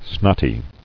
[snot·ty]